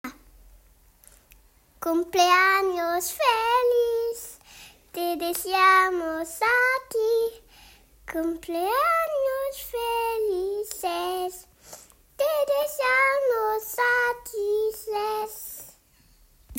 La chanson à chanter lors d'un anniversaire :
niveau-2-S1_L1_Chanson-de-anniversaire.m4a